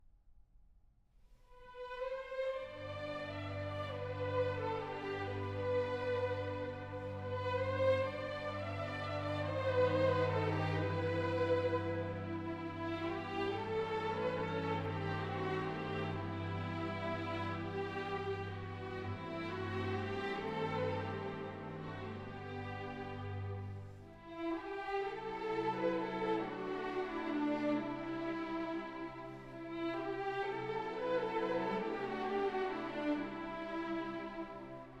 Classical Orchestral
Жанр: Классика